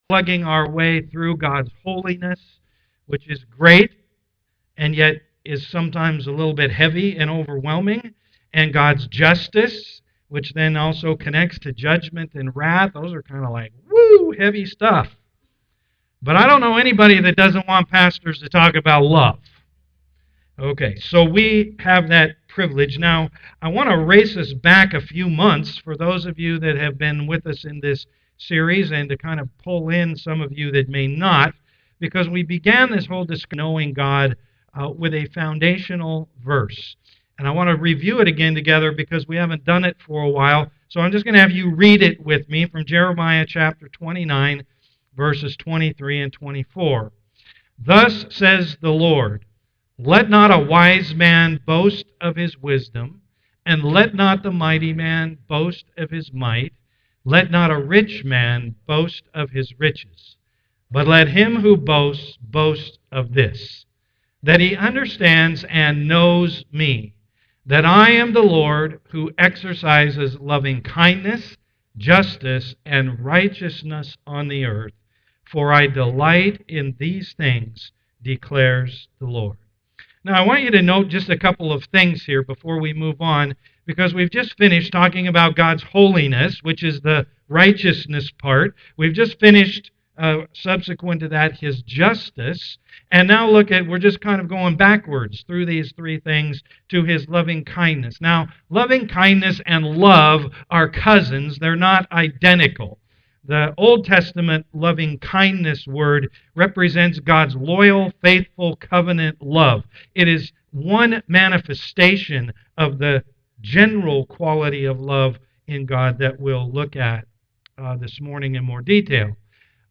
Know God Service Type: am worship Download Files Notes Bulletin Topics